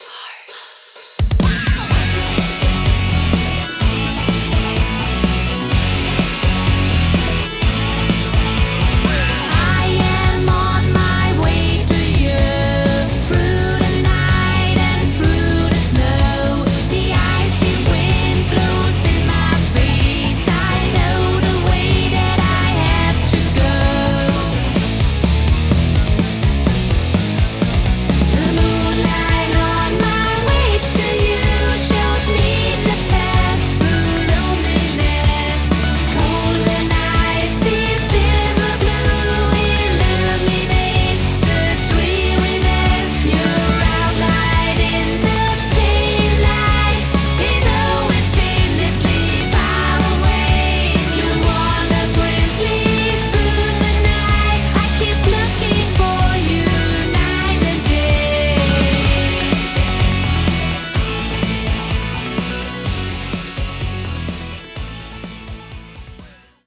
Die hören sich bei deiner Version schon etwas künstlich an und bei Landr wird das einfach noch...
Nachdem die unvoreingenommenen Ohren hier im Gesang noch Störendes "S", "t" und flangige Stimmen gehört haben, entschloss ich mich, die entsprechenden Störelemente so gut wie möglich zu eliminieren .